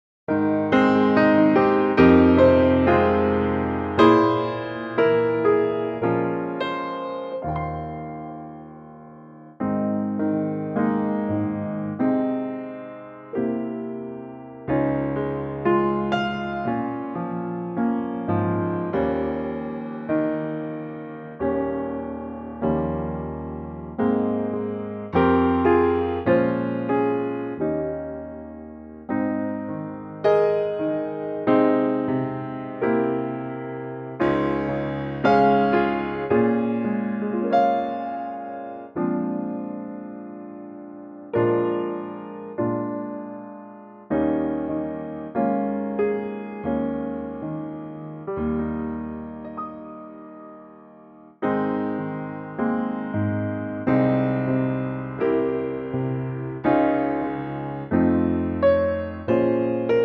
Unique Backing Tracks
piano intro and vocal in at 8 seconds
key - Ab - vocal range - Ab to C
Lovely piano only arrangement